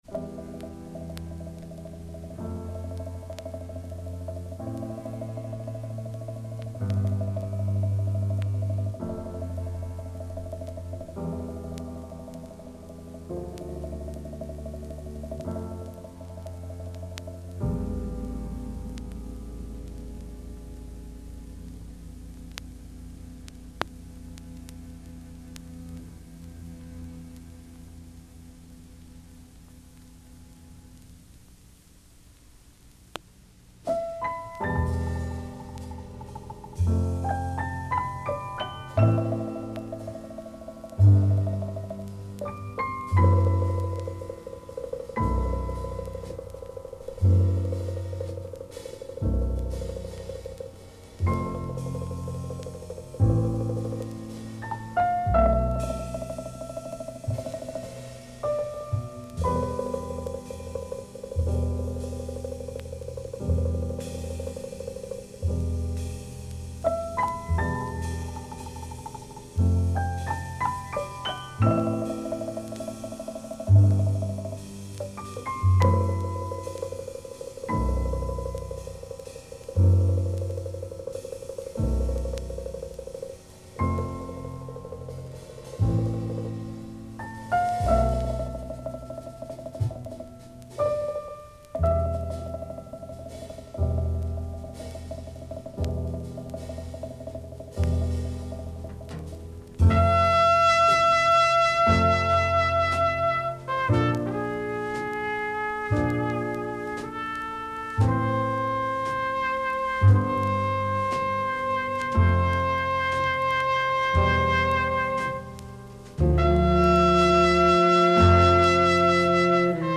composers and trumpet players.
” one of the most beautiful themes I’ve ever heard